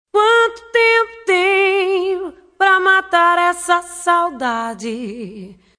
Trecho da música